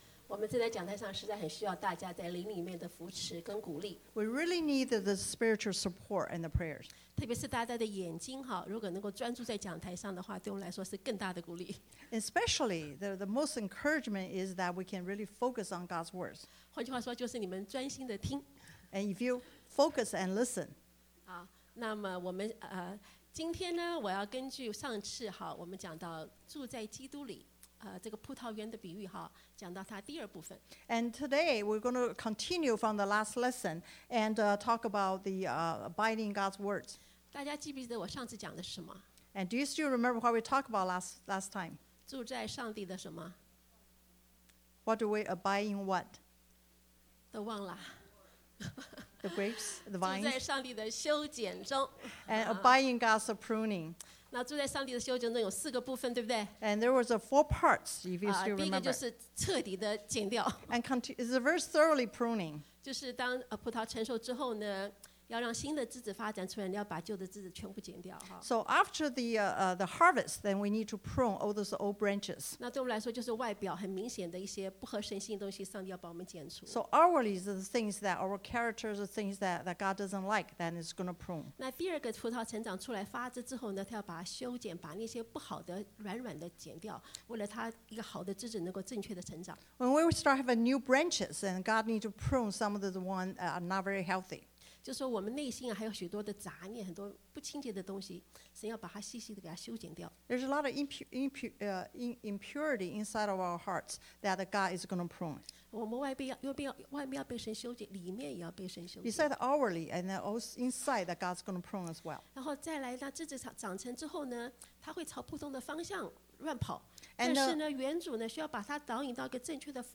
Bilingual Sermon